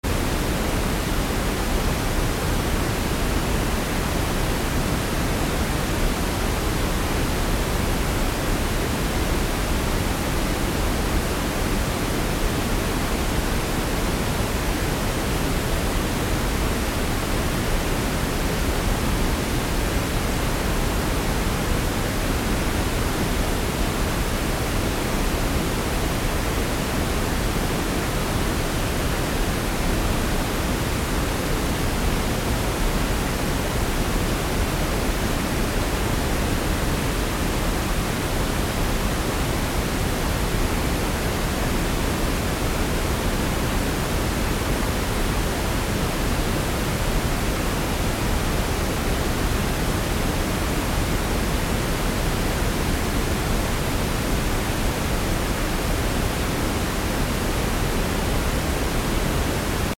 1 min of White Noise sound effects free download
1 min of White Noise for Babies | White Noise for Sleeping Baby | Baby white noise for babies